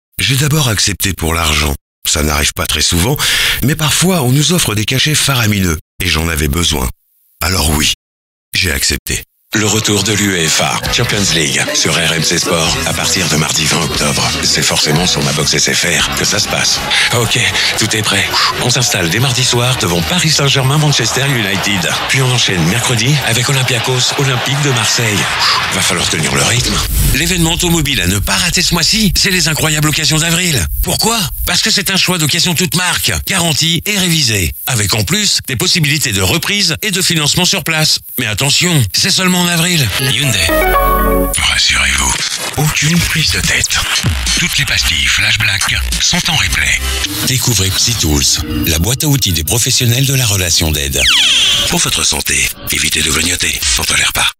Pix360-Voix-off.mp3